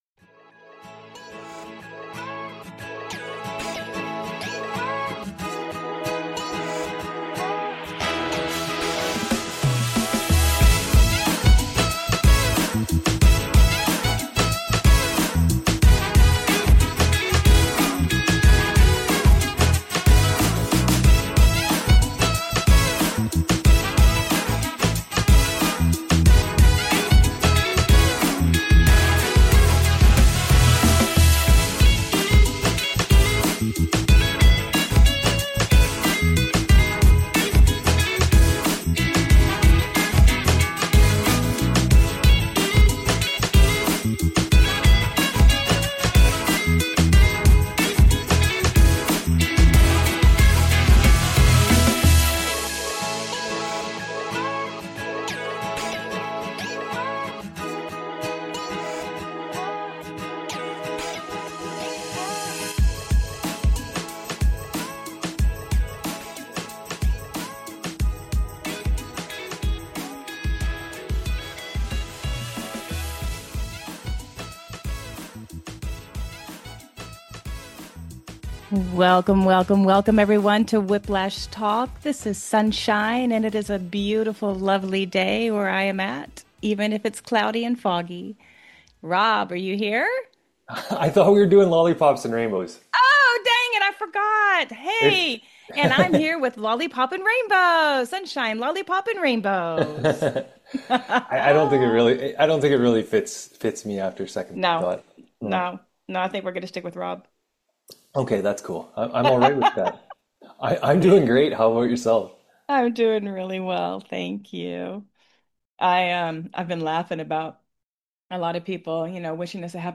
Whiplash Talk Show Headline